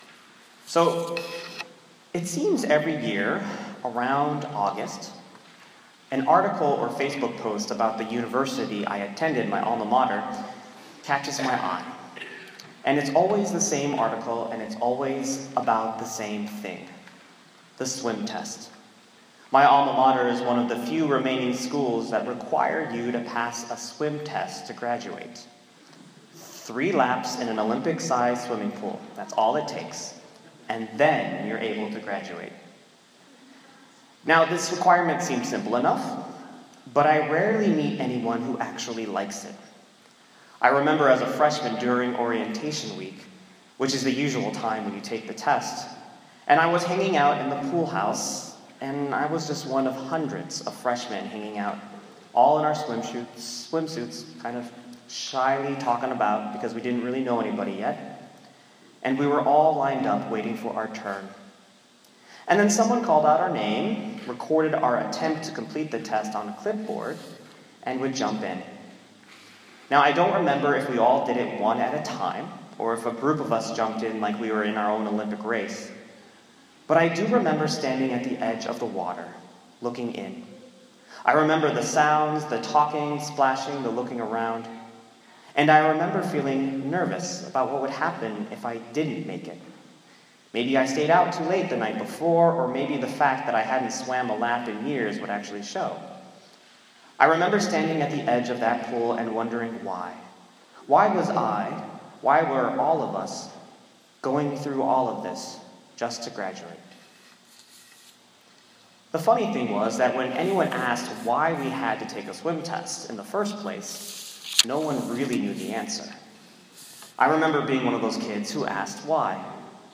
Mark 1:4-11 My sermon from the Baptism of Jesus (January 11, 2015) on Mark 1:4-11.